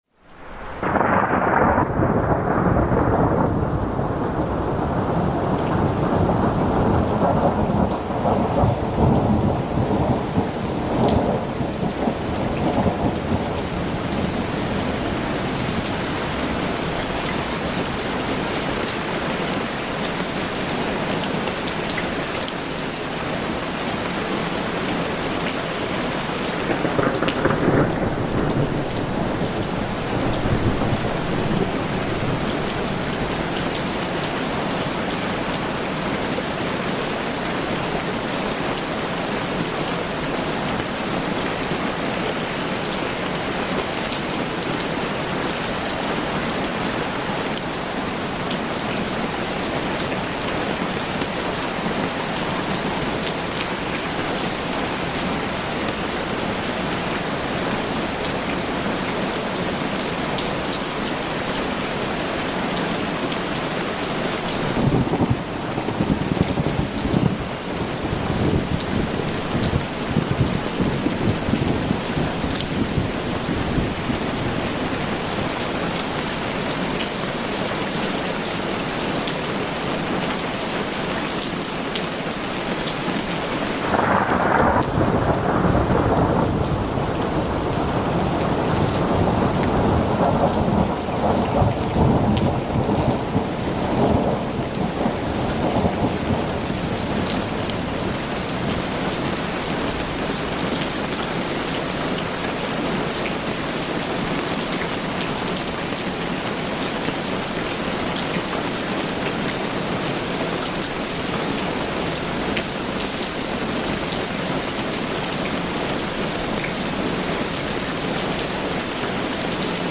Mountain Storm Immersion—Rolling Thunder & Powerful Rainfall